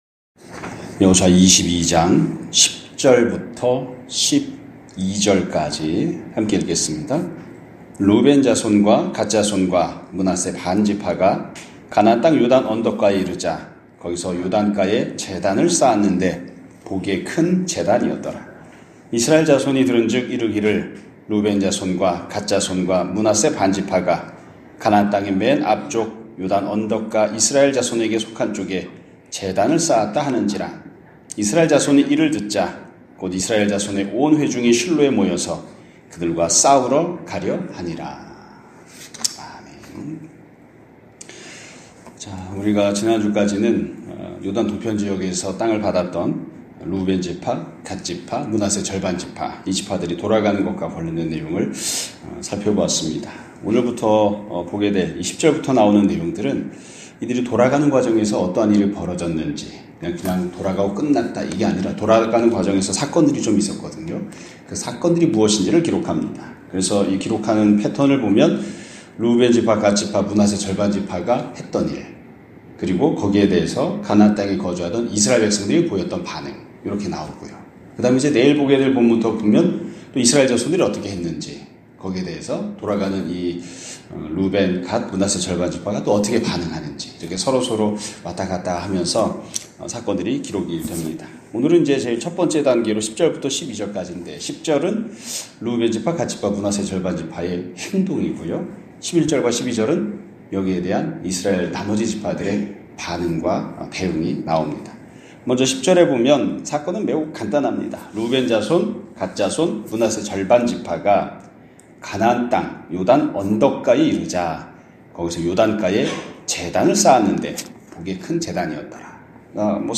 2025년 2월 10일(월요일) <아침예배> 설교입니다.